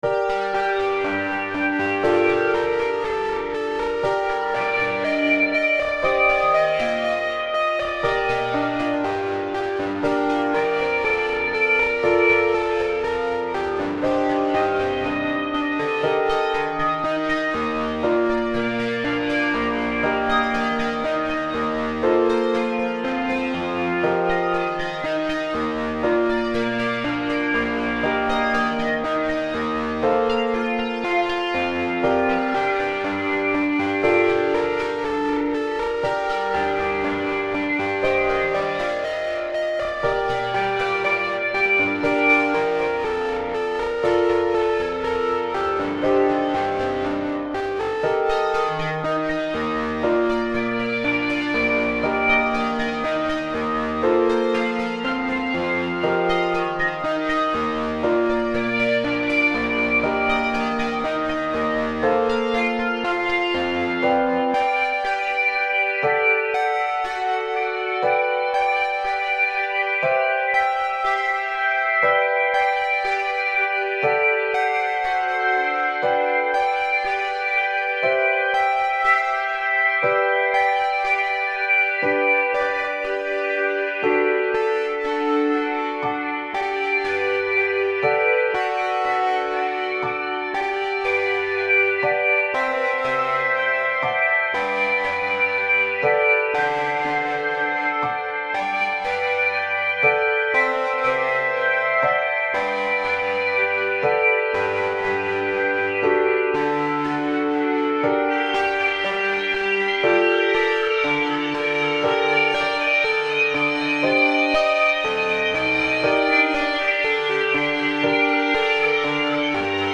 Follow the crowd (instrumental) - Orchestrated: 19th-25th September 2011.
This involved distorted guitars chasing a piano around so I called it Follow the crowd.
It was playing the chord sequence and I used it to make sure the rest of it held together.